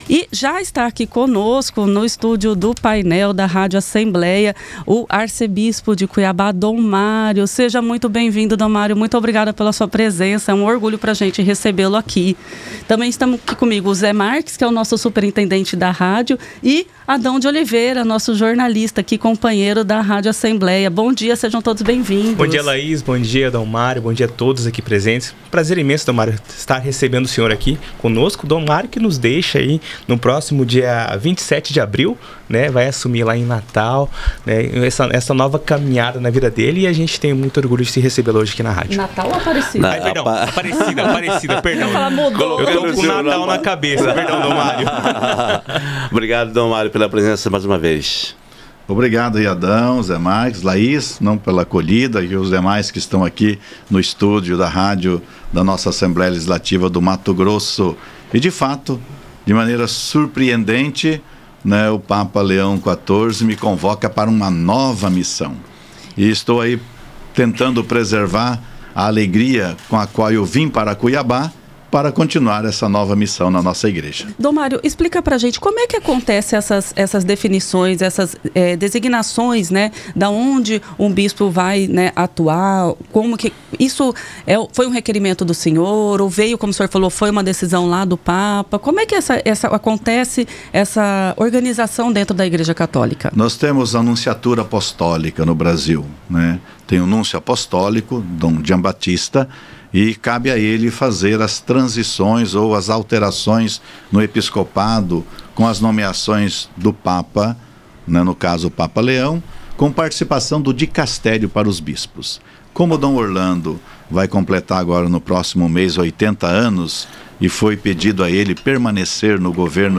Entrevista com Dom Mário, arcebispo de Cuiabá, sobre sua ida para Arquidiocese de Aparecida (SP)